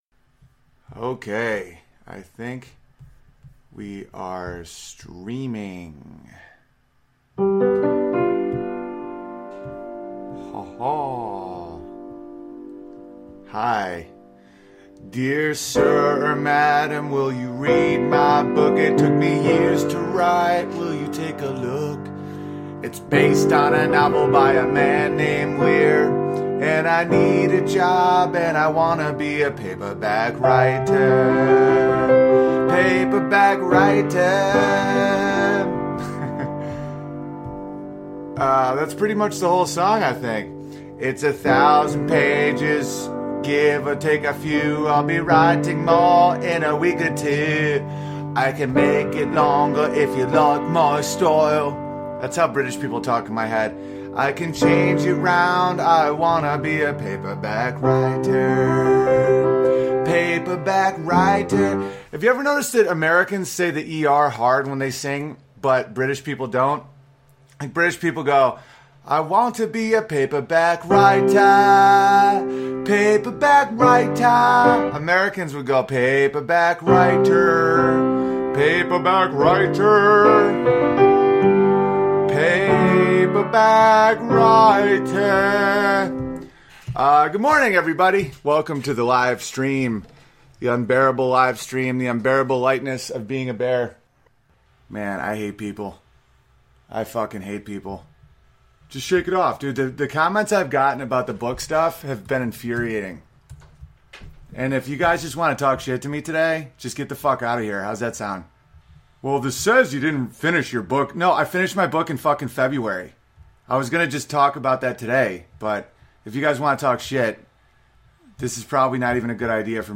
Some reading from the soon to be self published " The Good Fight"Will you bend?